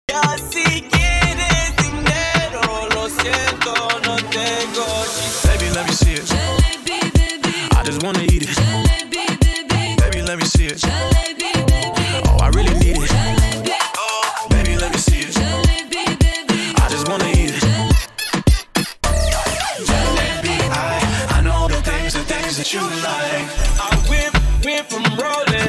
Open format, can play any genres and style.